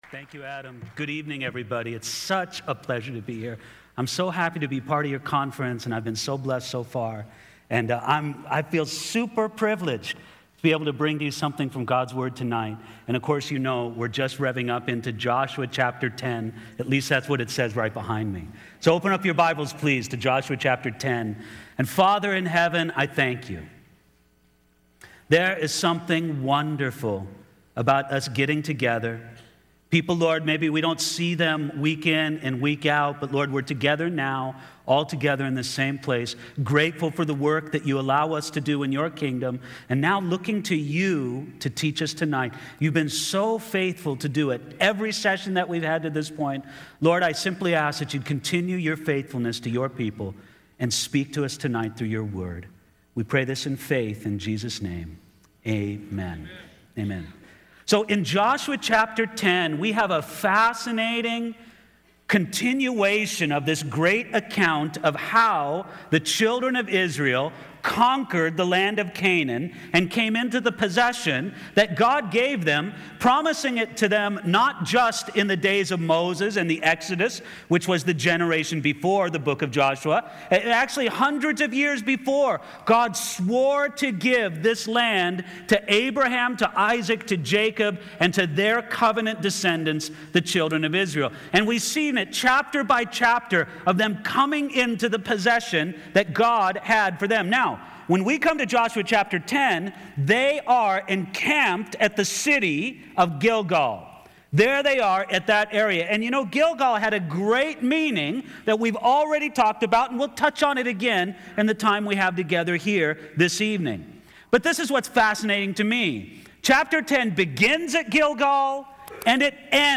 Southwest Pastors and Leaders Conference 2013